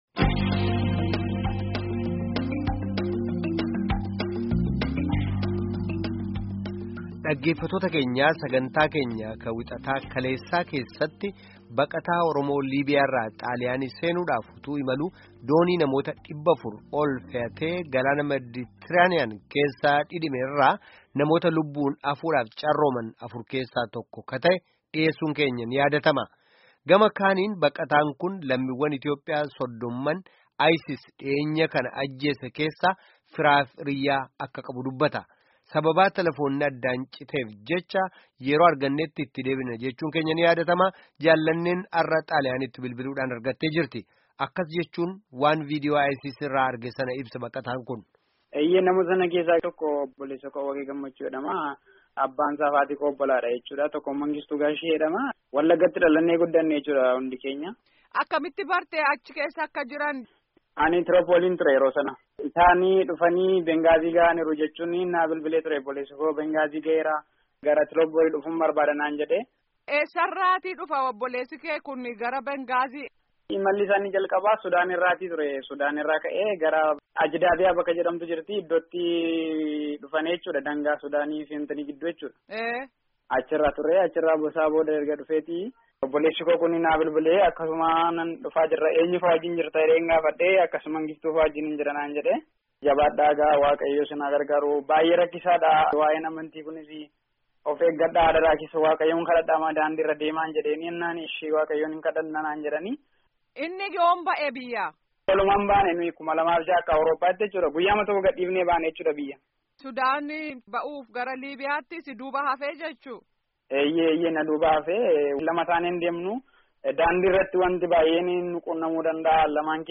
Gaaffii fi deebii geggeedffame kutaa 2ffaa armaan gaditti caqasaa